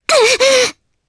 Mirianne-Vox_Damage_jp_02.wav